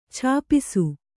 ♪ chāpisu